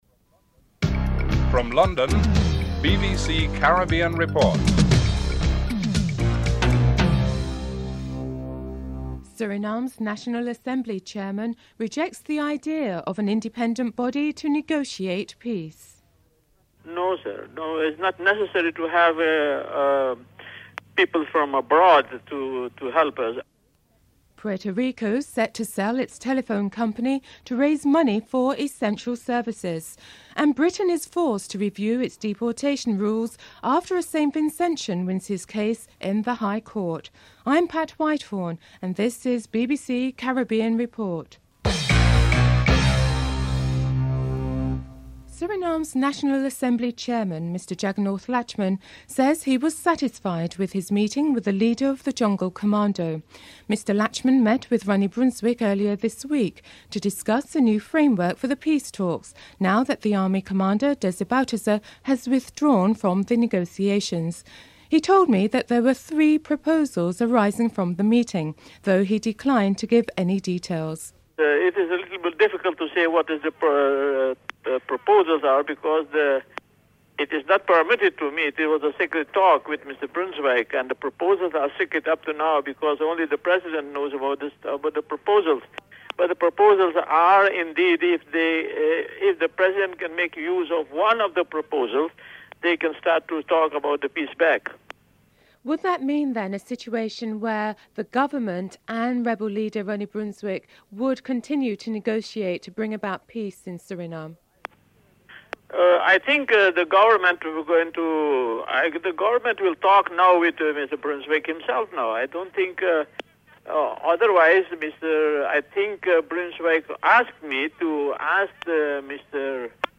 anchor
interviewee
correspondent
Interview